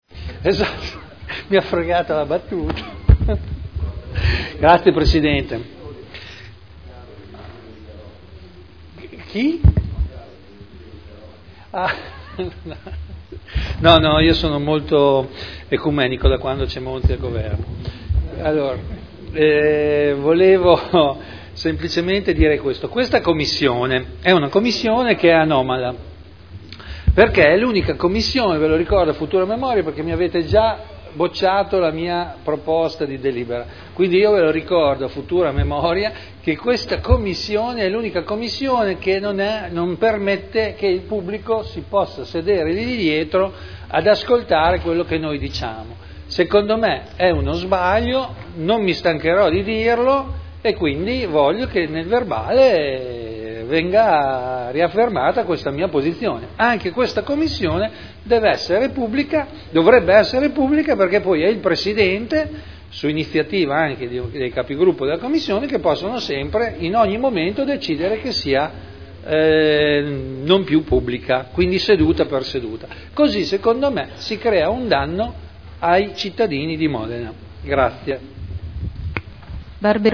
Seduta del 12 dicembre Commissione consiliare permanente Affari Istituzionali – Modifica Dibattito